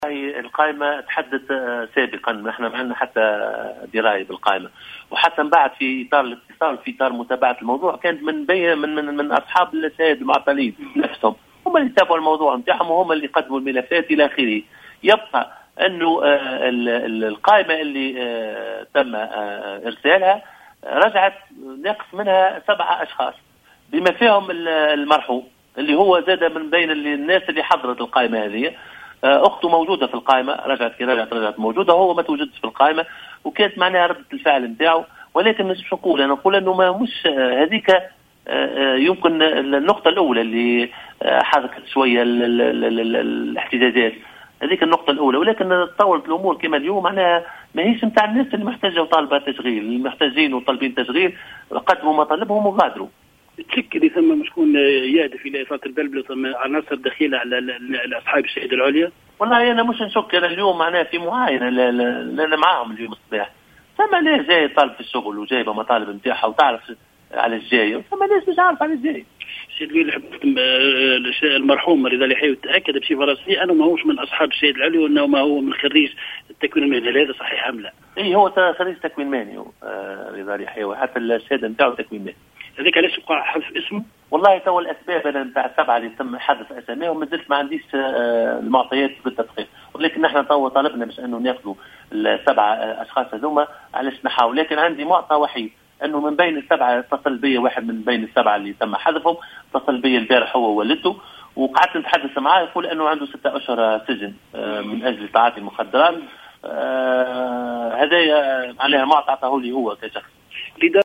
تصريح لمراسل "الجوهرة أف أم"